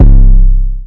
808 3 [ in her mouth ].wav